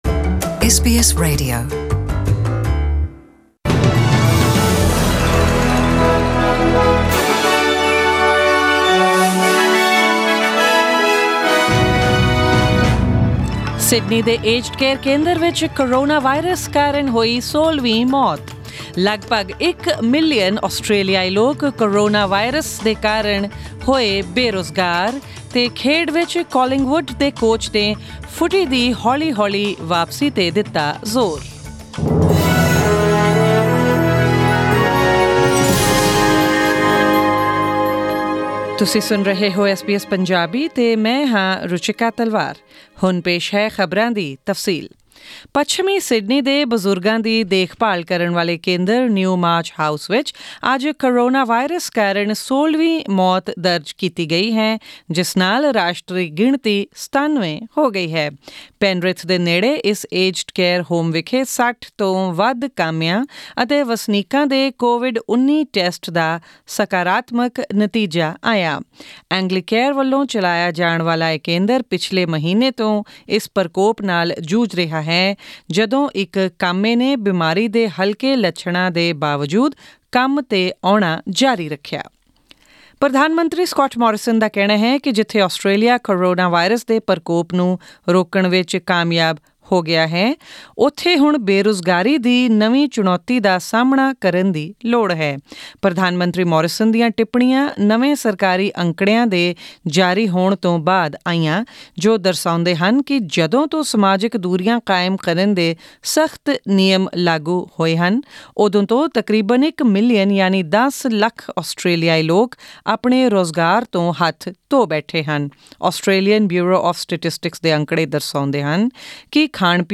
Australian News in Punjabi: 5 May 2020